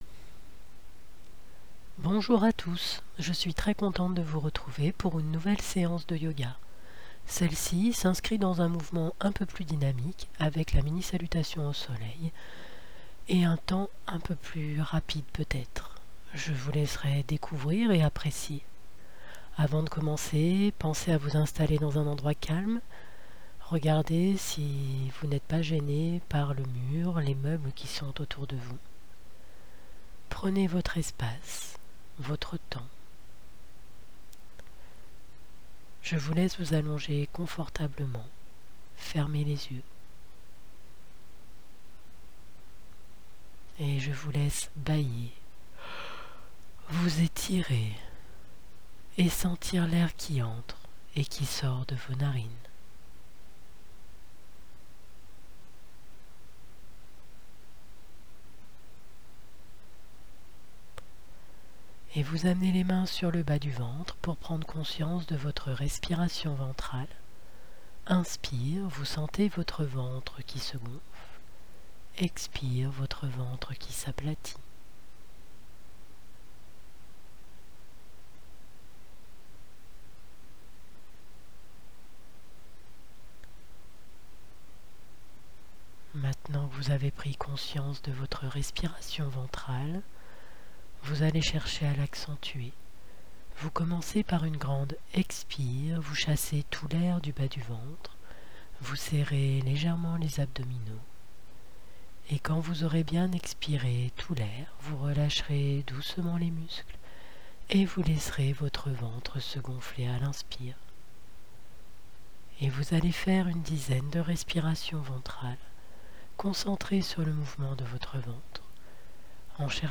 Cours de Yoga tout niveau
SEANCE-MINI-SALUTATION-AU-SOLEIL.m4a